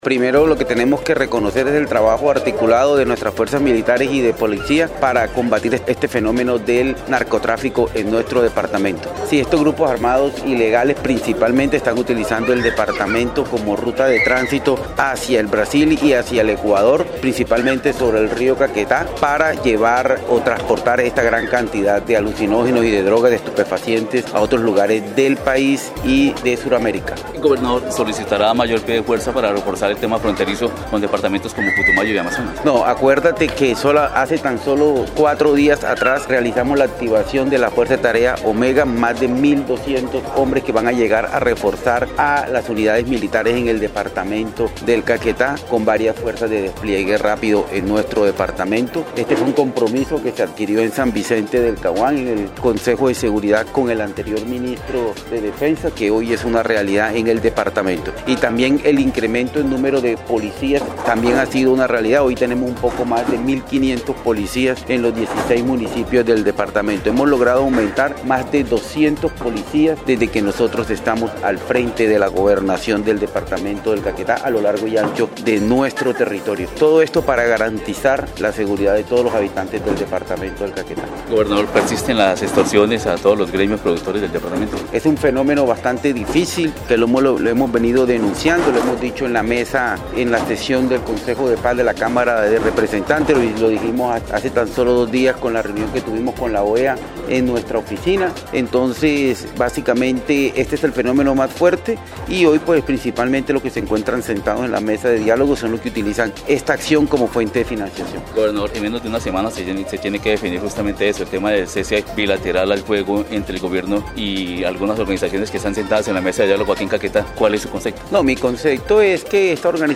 Así lo dio a conocer el gobernador del departamento, Luis Francisco Ruiz Aguilar.